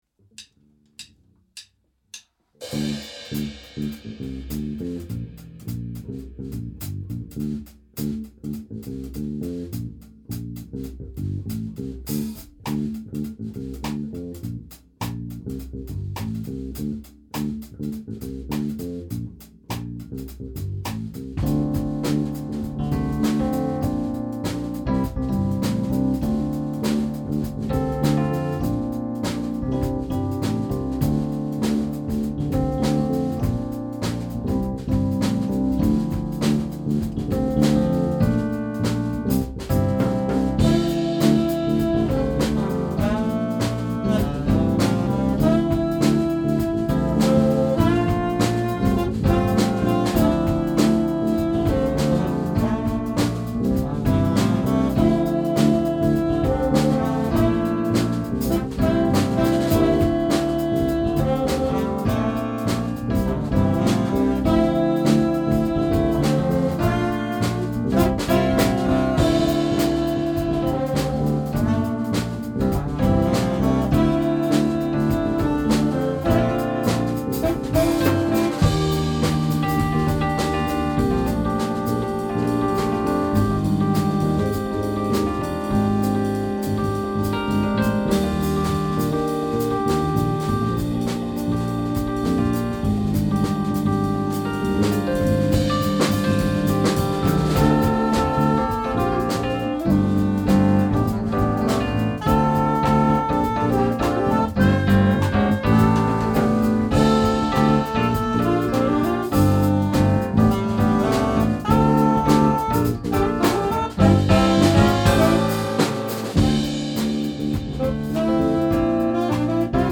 · Genre (Stil): Jazz